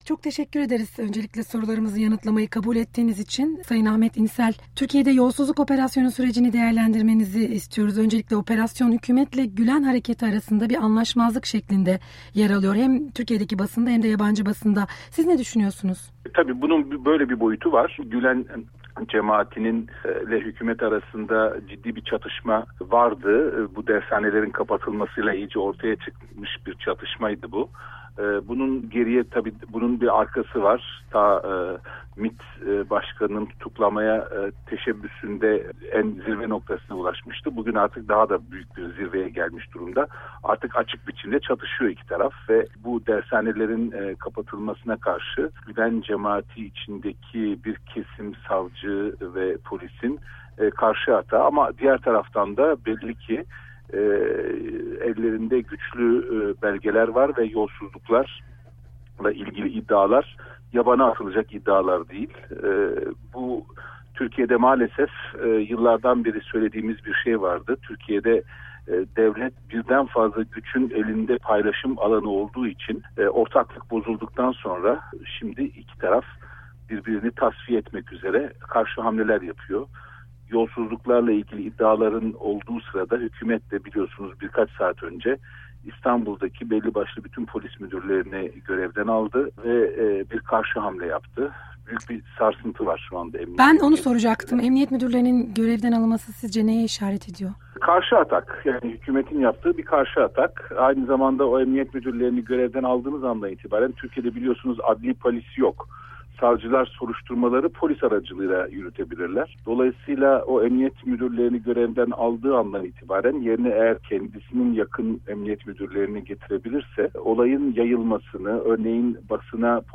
Ahmet İnsel ile Söyleşi